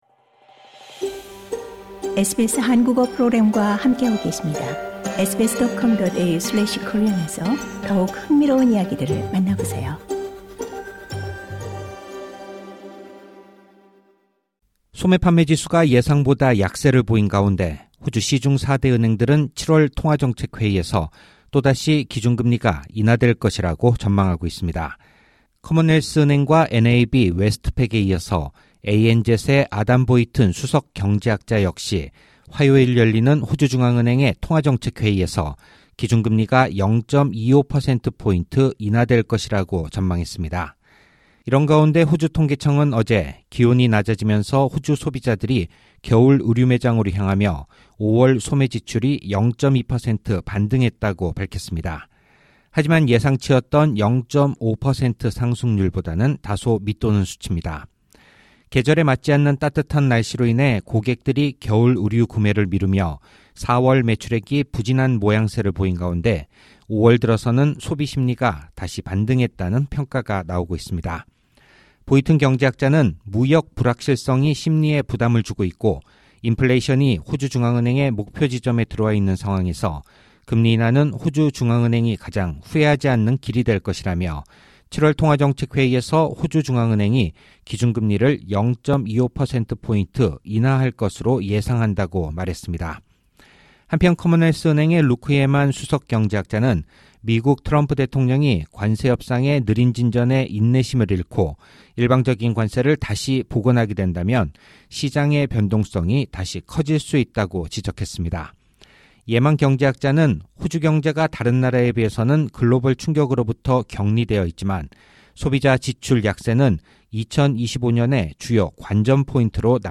SBS Korean 11:24 Korean 상단의 오디오를 재생하시면 뉴스를 들으실 수 있습니다.